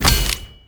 sfx_reinforce 02.wav